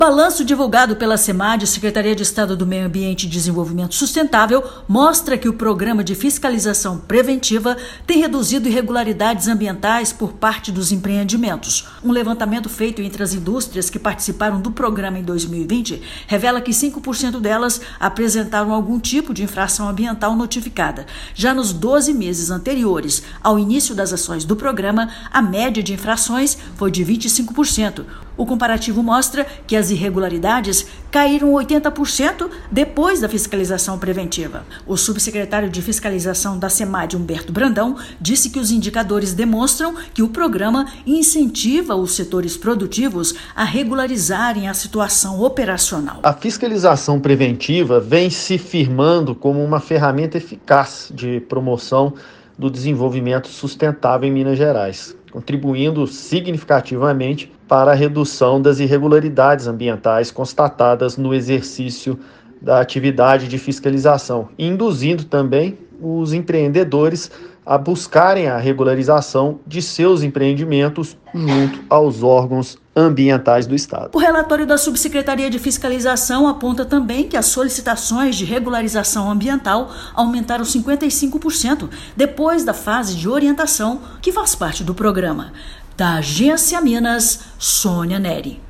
Balanço divulgado pela Secretaria de Estado de Meio Ambiente e Desenvolvimento Sustentável (Semad) mostra que o Programa de Fiscalização Preventiva contribui para a redução de 80% das infrações ambientais em indústrias. Ouça matéria de rádio.